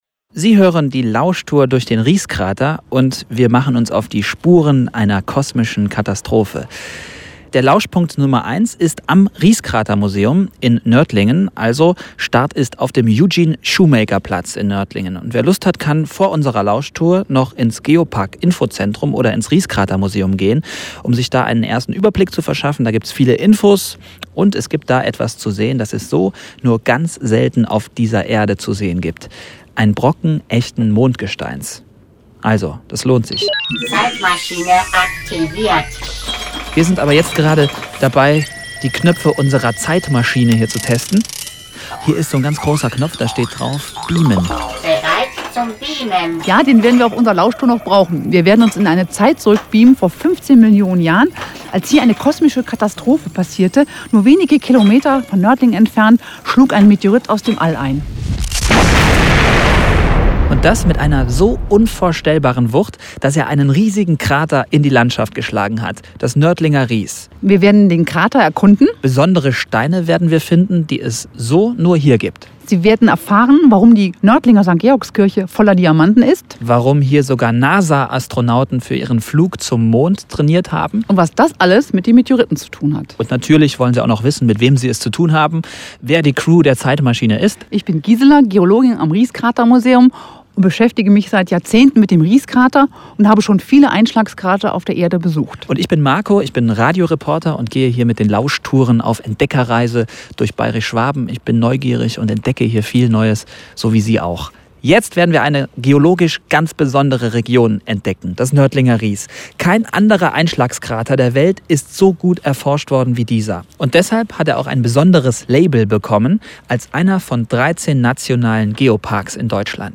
Die Auto-Audio-Tour bringt Sie zu den spannendsten Stellen des Meteoritenkraters.